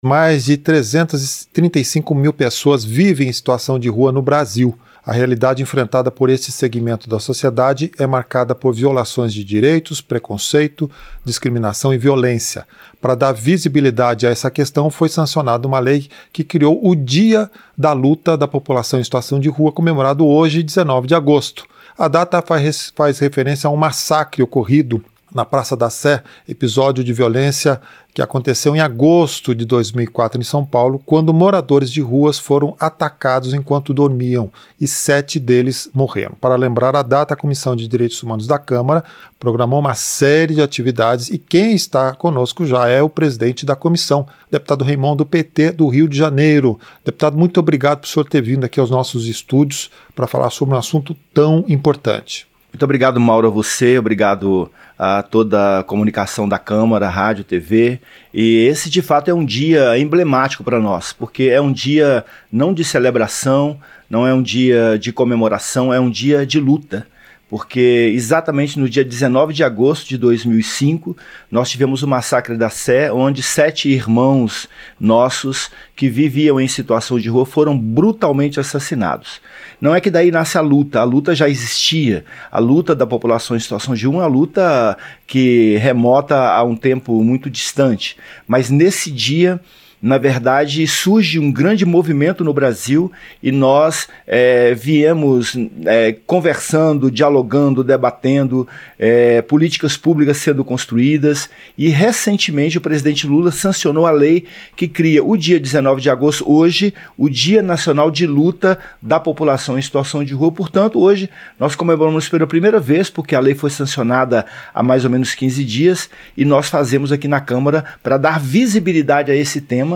Entrevista - Dep. Reimont (PT-RJ)